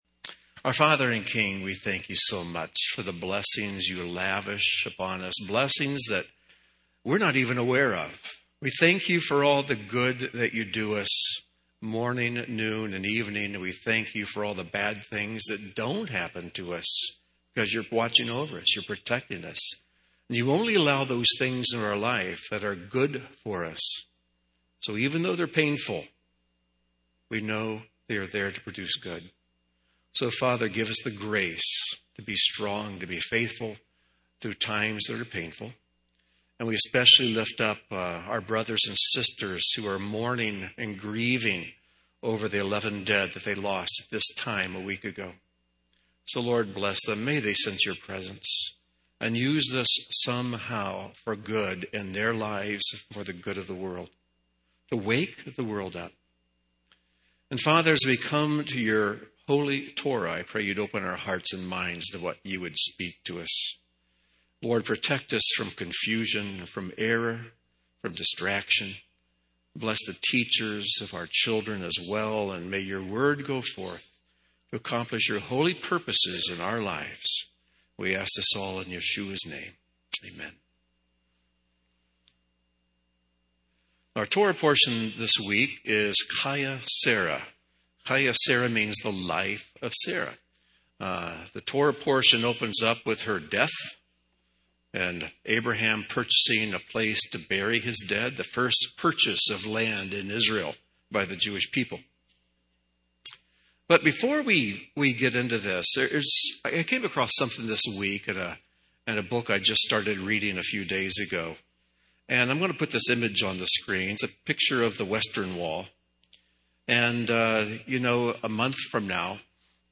This teaching starts with an admonition to guard against taking the holy for granted.